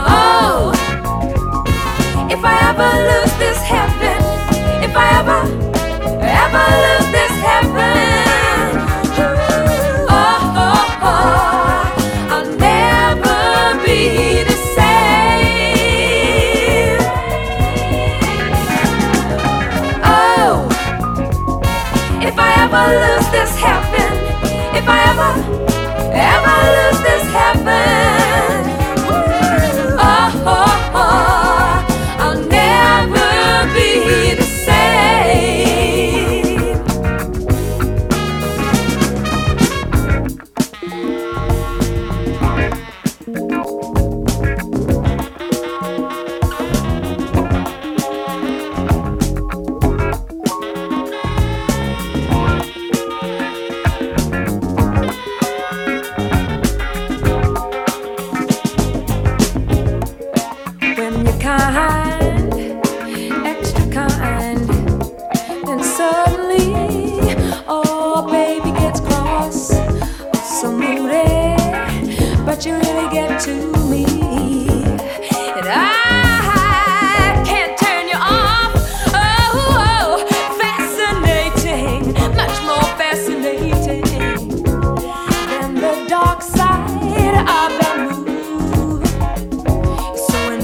SOUL / SOUL / FREE SOUL / SOFT ROCK / S.S.W.